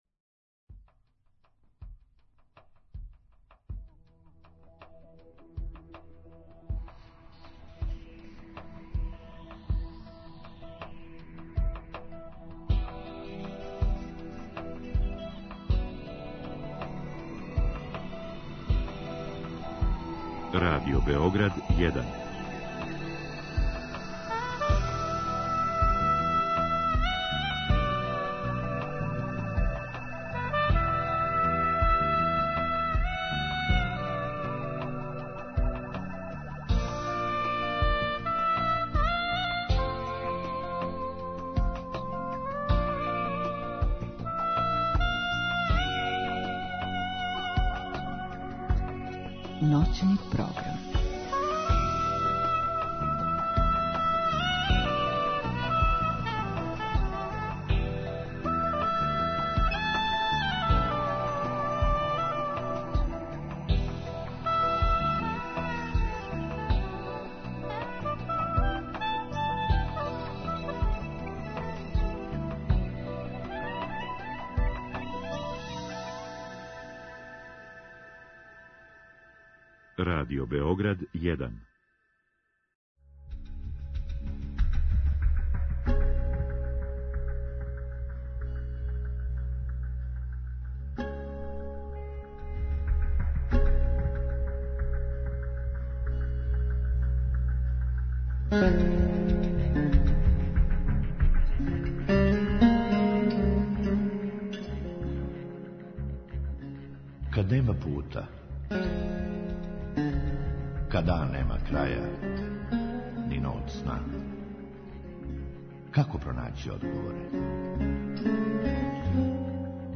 У другом сату слушаоци могу да постављају питање госту у директном програму у вези са темом.